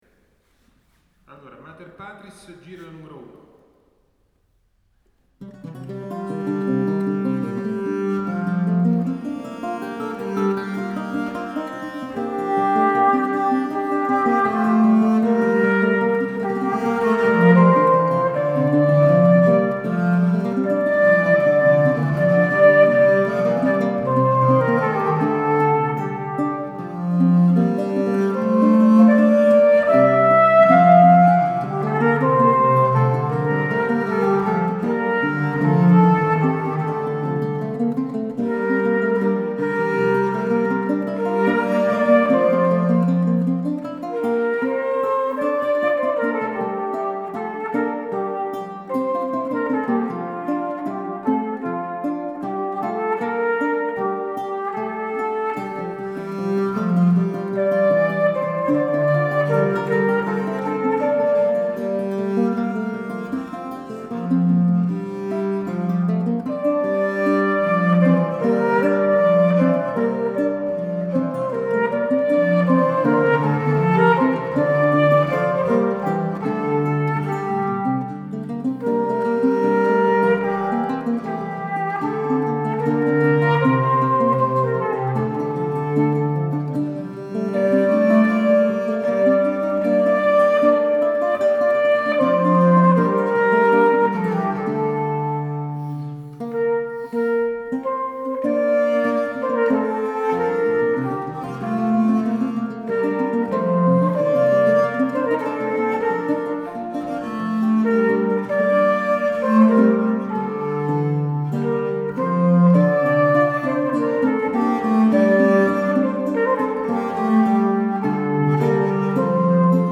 the lute
Here you are some fun pictures of them at work and a preview of one piece : the Mater Patris mottet for tablature.